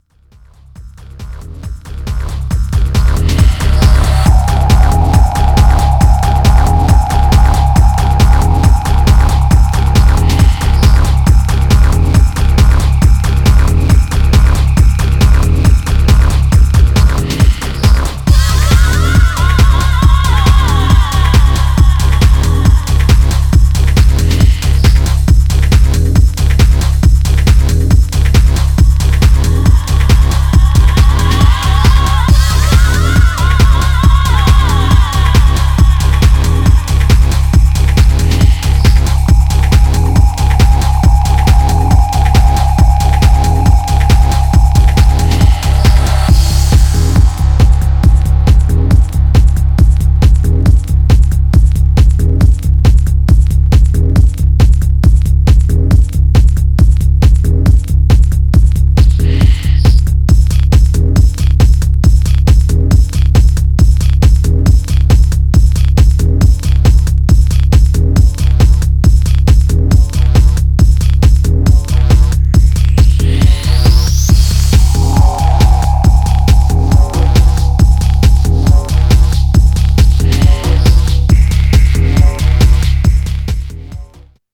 Styl: Progressive, House, Breaks/Breakbeat